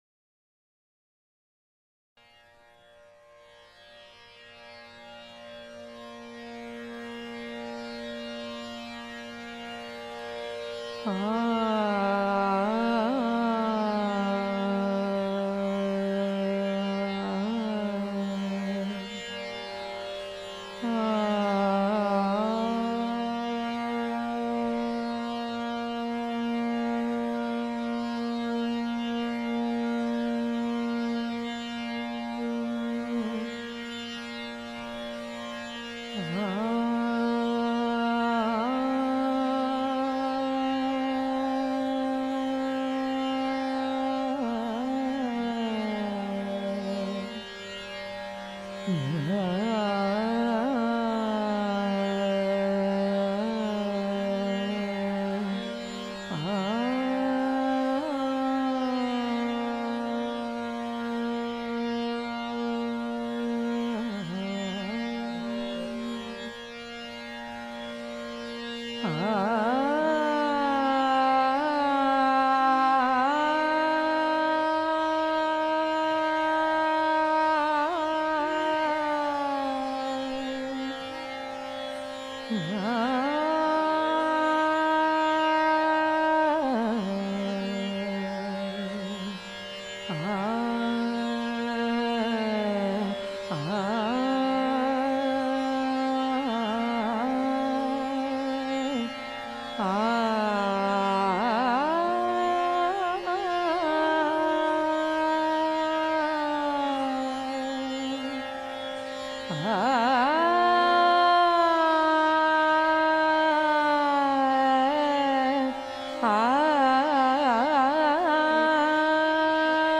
devi-durgay-raag-durga.mp3